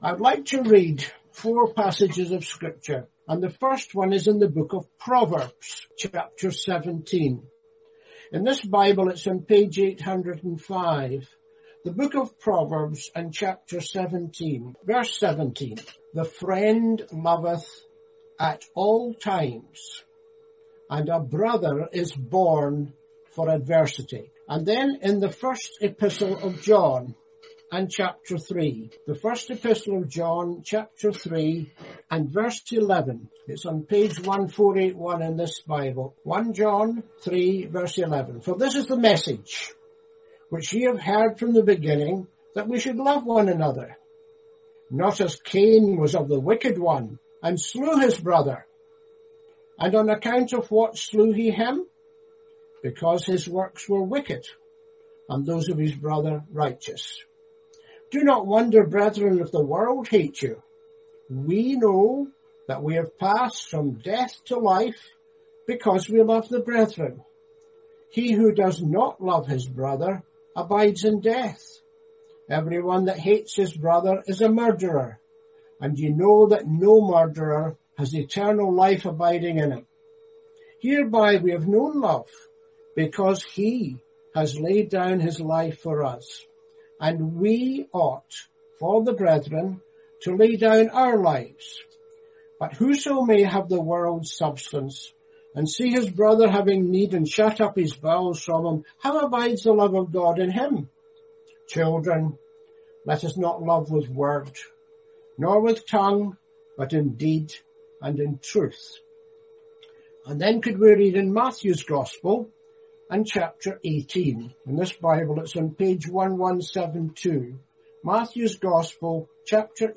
Christians can experience fellowship, worship and adversity with brothers and sister in Christ. In this address, you will hear what the scriptures say about the value of a brother and sister in Christ.